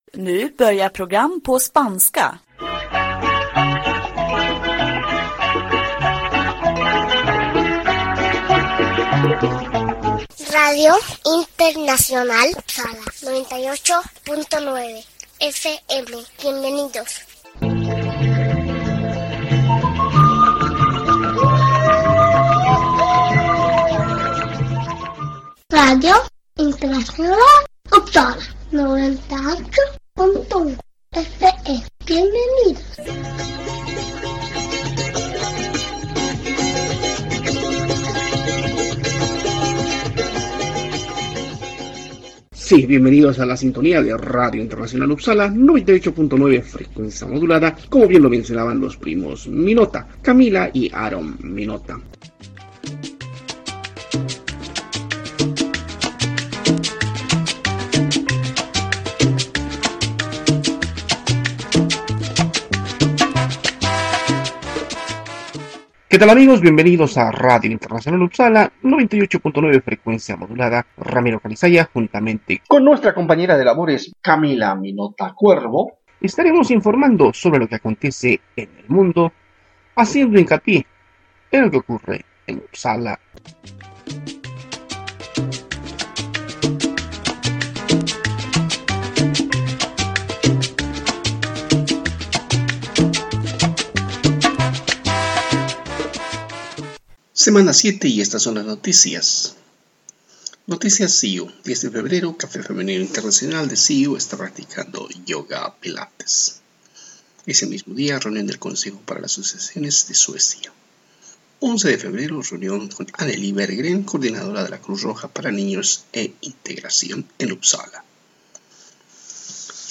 RADIO SIU produce radio en español y además en otros 10 diferentes idiomas.